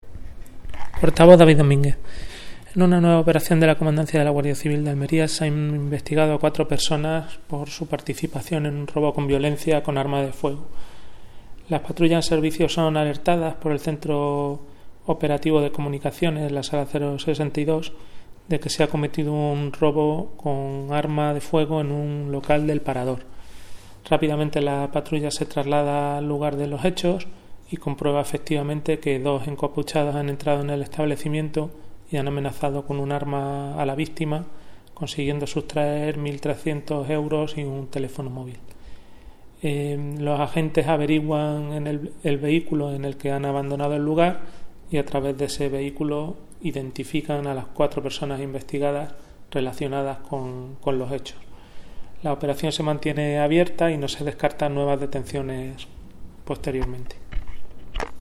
Declaraciones: